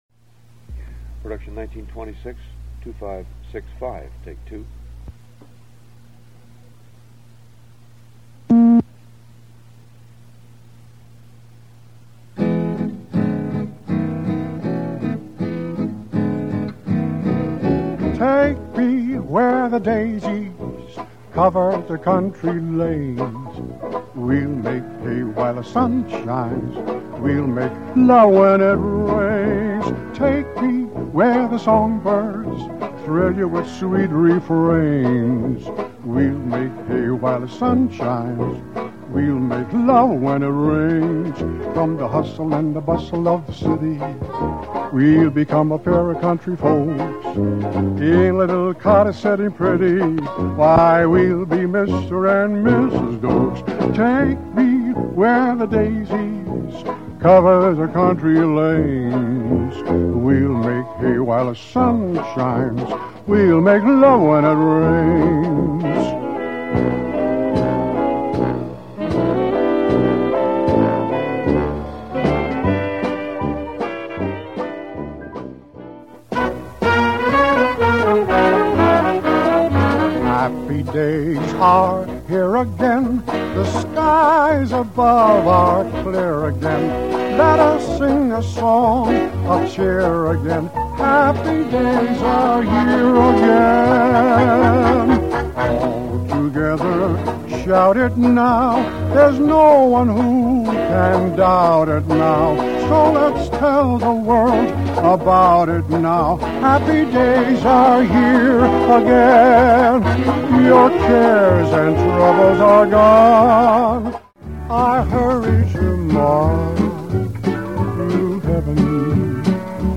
(voice only)